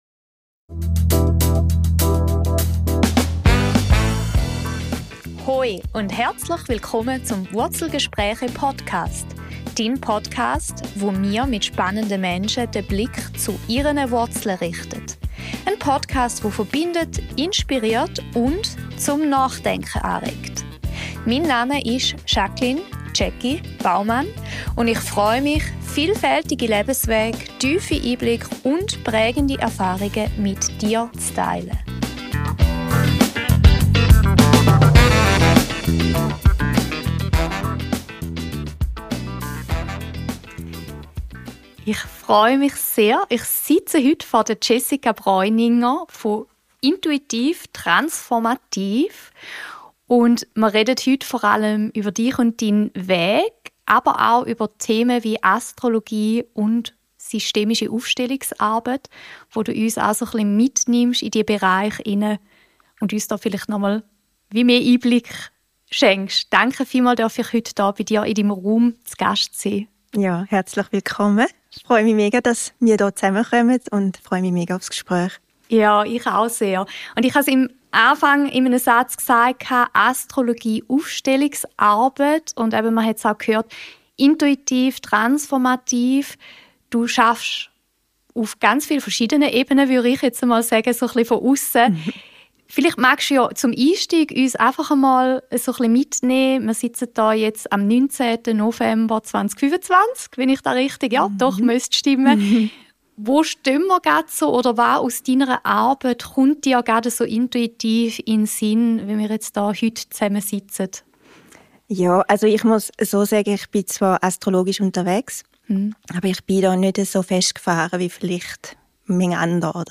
Ein tiefes und zugleich leichtes Gespräch über Hingabe, Wandlung und das Öffnen innerer Räume – zwischen Himmel und Erde, Verstand und Intuition, Schmerz und Vertrauen.